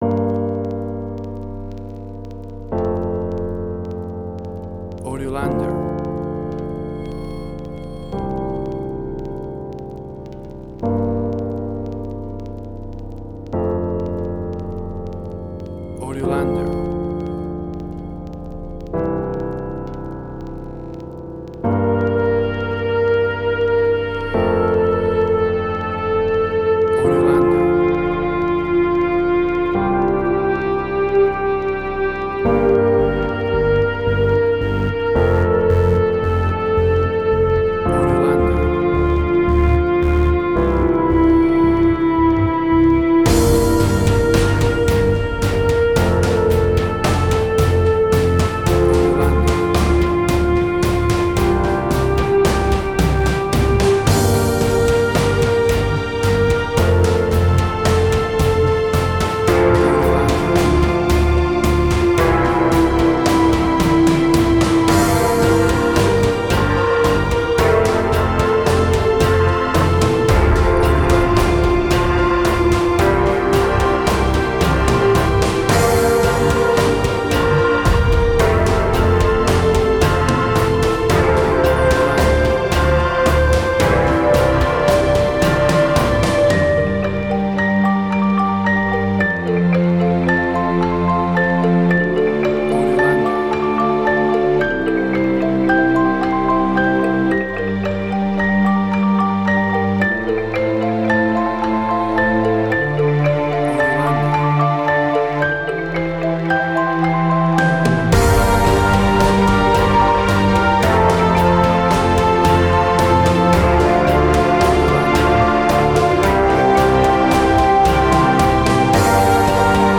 Post-Electronic.
Tempo (BPM): 111